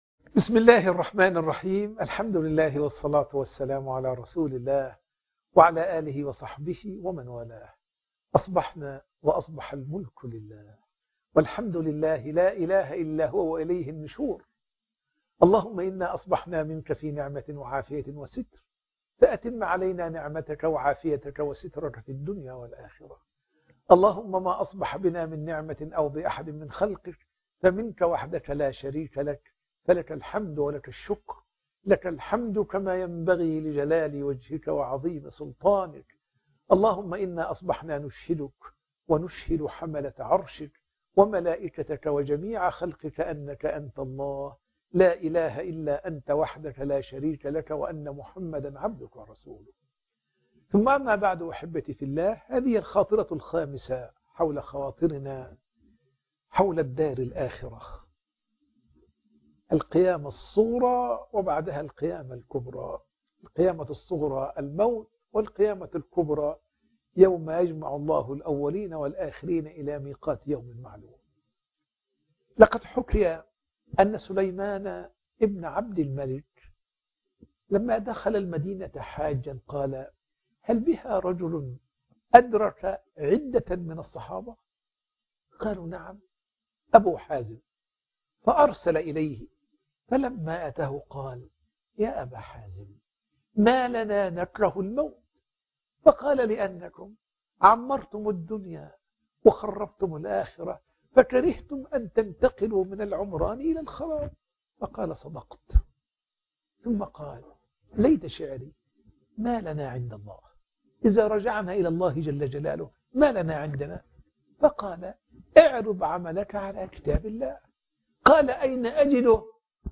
الاستعداد للدار الآخرة 5 (درس بعد الفجر